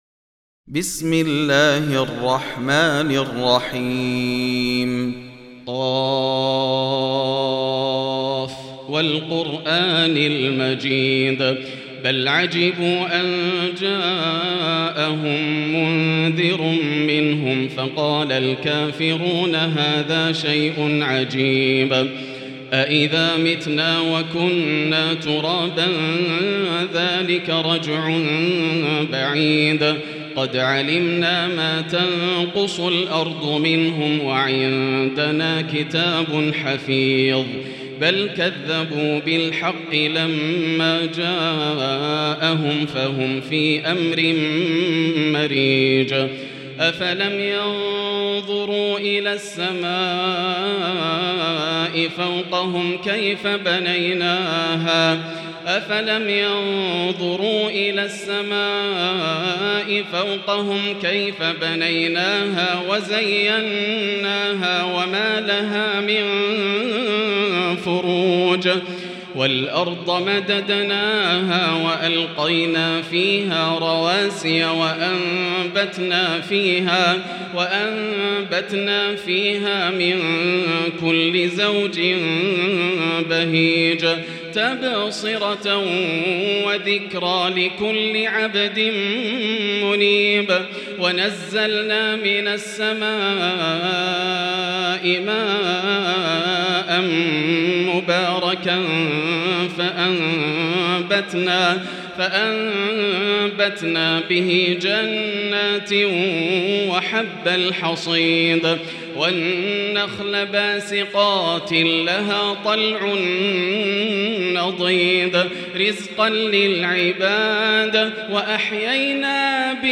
المكان: المسجد الحرام الشيخ: فضيلة الشيخ ياسر الدوسري فضيلة الشيخ ياسر الدوسري ق